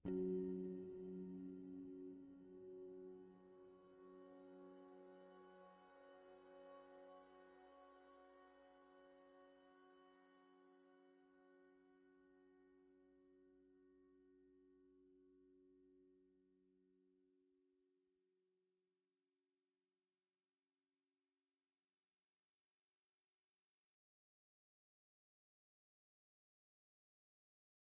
piano_piano_string1.ogg